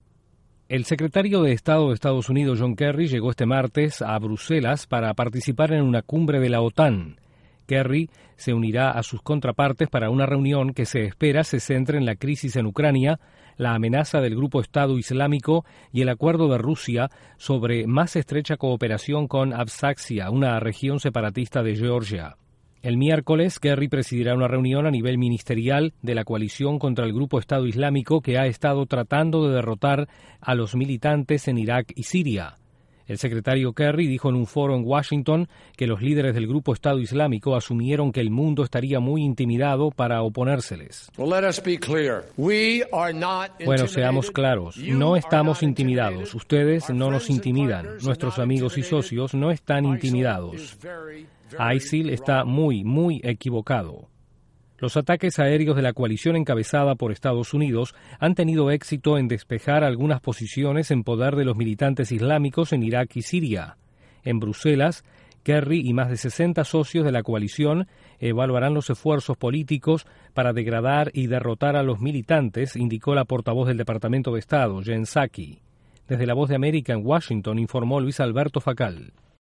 El secretario de Estado norteamericano, John Kerry, llegó a Bruselas para una cumbre de la OTAN. Desde la Voz de América en Washington informa